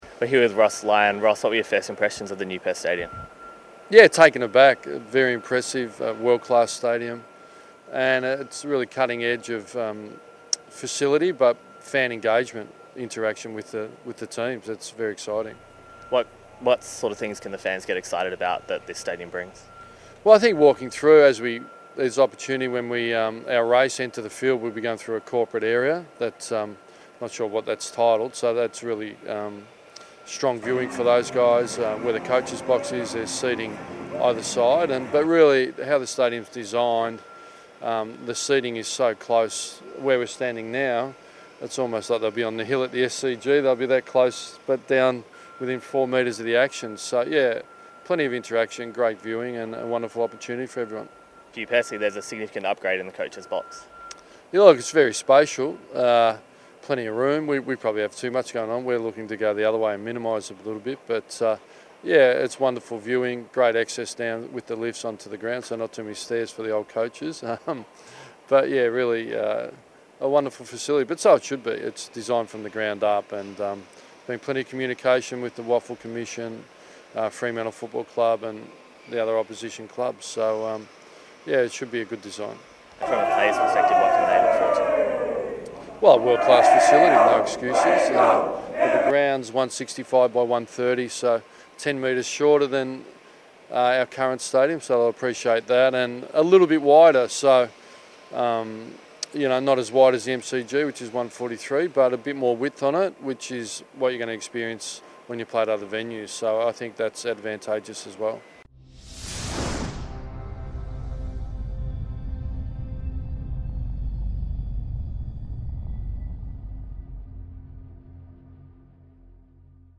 Ross Lyon chats to Docker TV after visiting Perth Stadium.